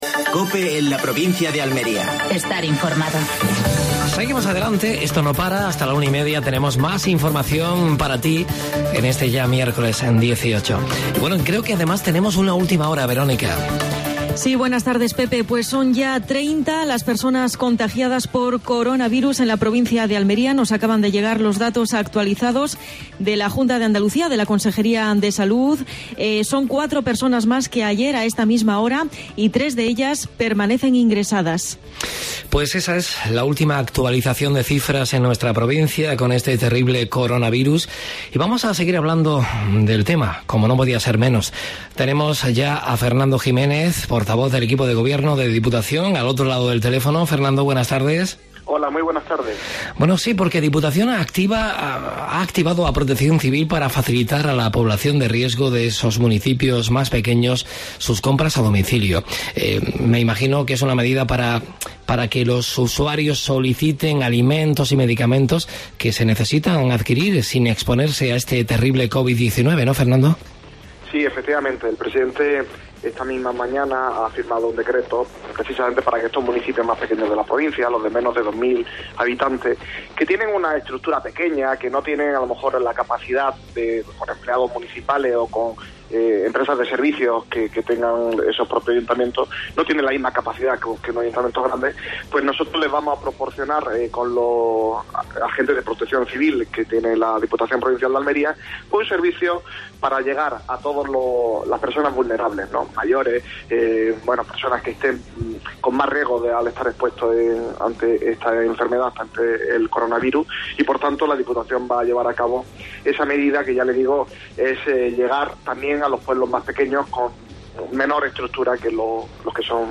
Actualidad en Almería. Entrevista a Fernando Giménez (diputado de Presidencia de la Diputación Provinicial de Almería).